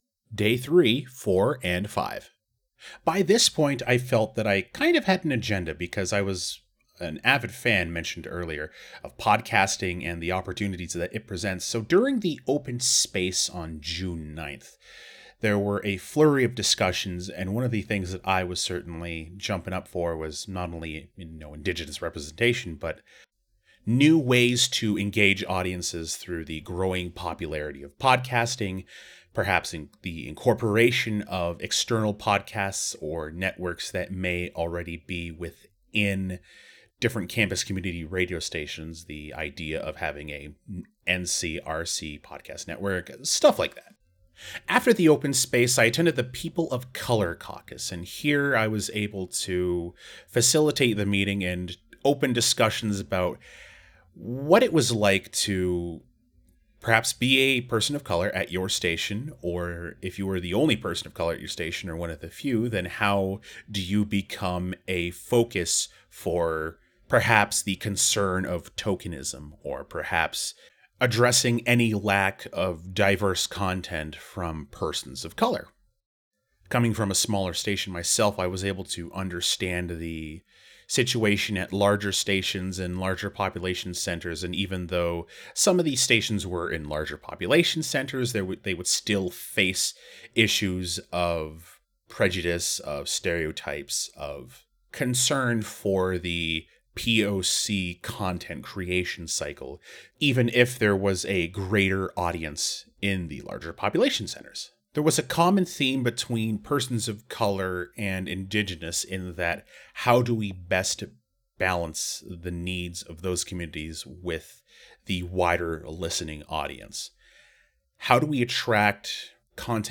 Type: Commentary
320kbps Stereo